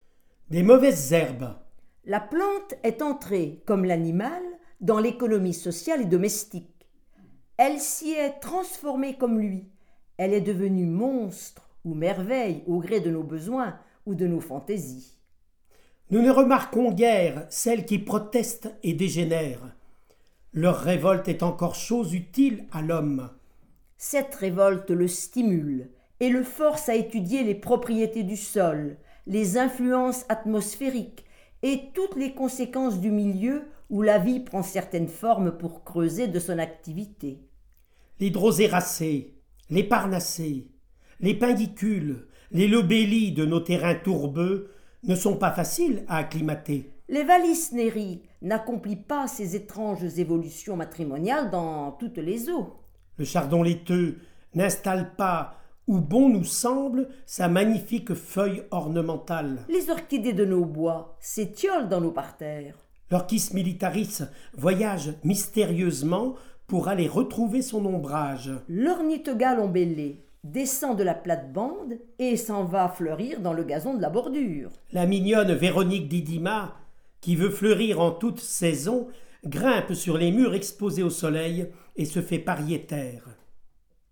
• Lectures des textes de George Sand sur les Sciences, réalisées à l’occasion du colloque George Sand et les sciences et vie de la terre et de l’exposition au Muséum d’histoire naturelle de Bourges